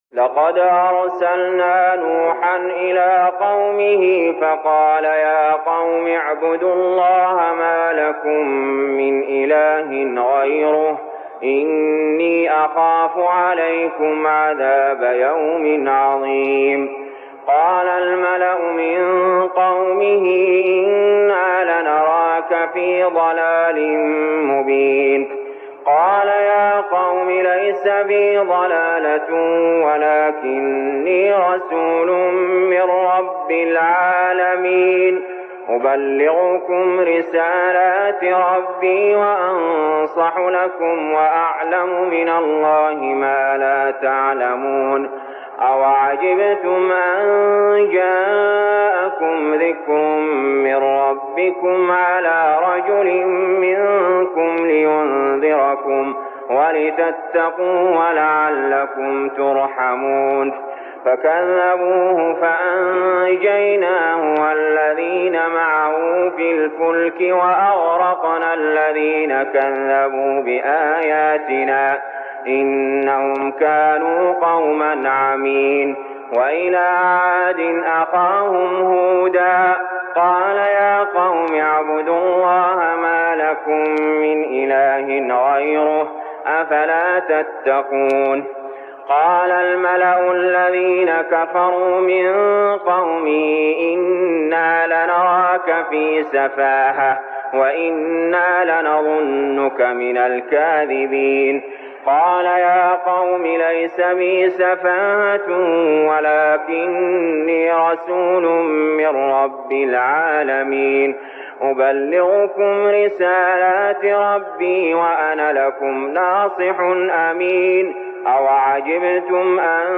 صلاة التراويح ليلة 9-9-1407هـ سورة الأعراف 59-162 | Tarawih prayer Surah Al-A'raf > تراويح الحرم المكي عام 1407 🕋 > التراويح - تلاوات الحرمين